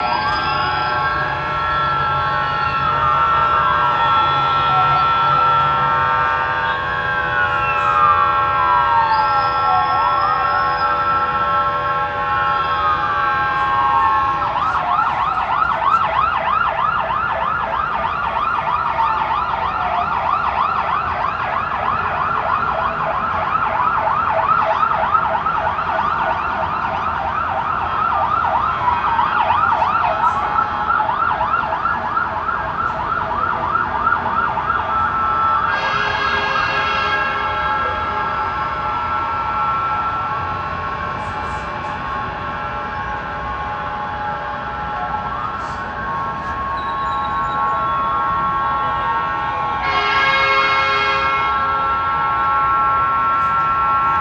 Fire Fighters Atmos
Alarm Ambience City Fighters Fire Passing Public Road sound effect free sound royalty free Sound Effects